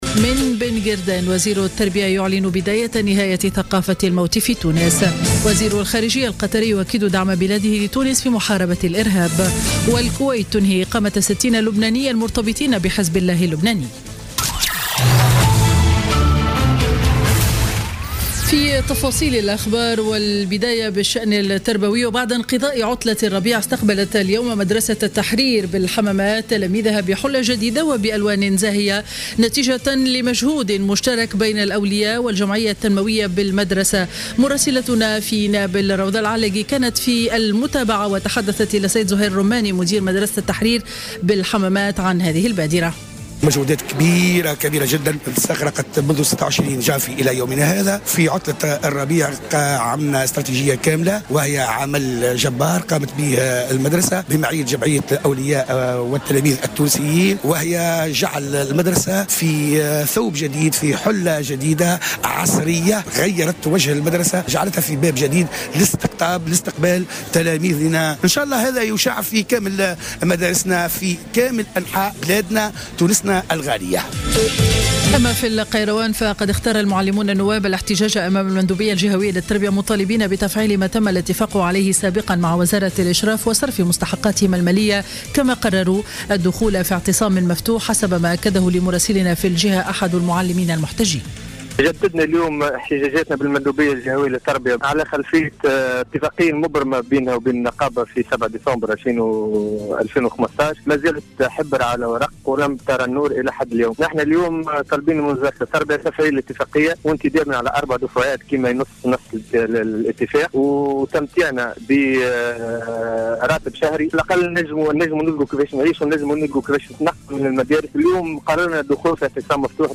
Journal Info 12h00 du lundi 28 Mars 2016